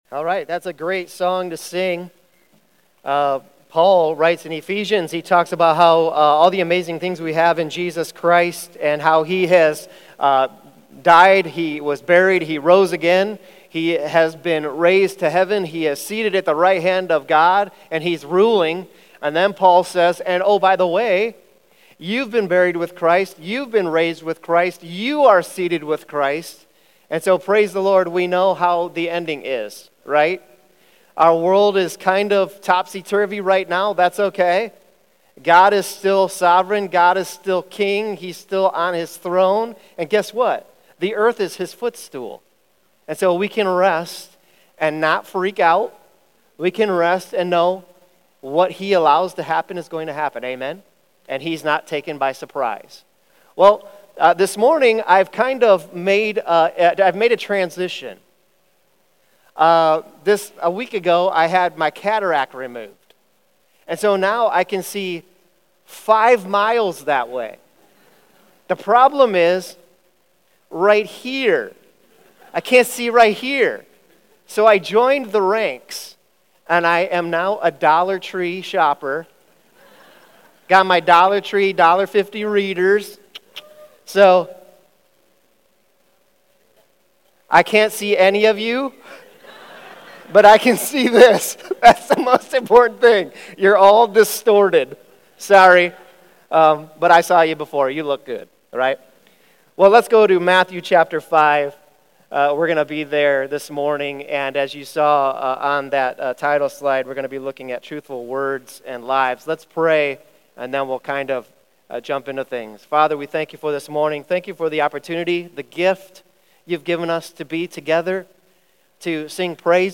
Sermon Questions Read Matthew 5:33-37 and Matthew 23:16-22. 1 - God's original design for human speech is that it be marked by straight-forward truth-telling.